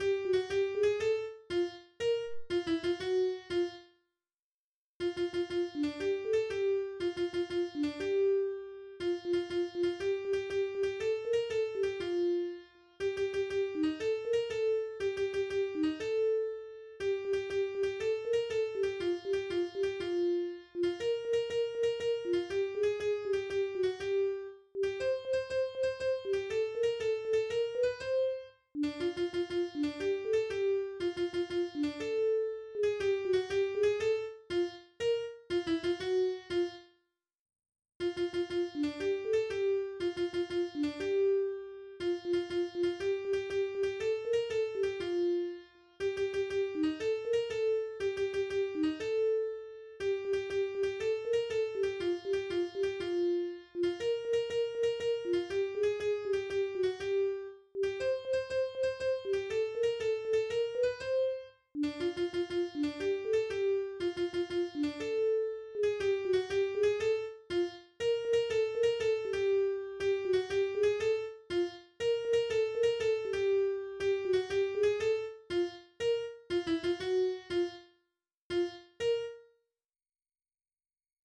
I also tweaked the sound shaper to produce quieter sounds.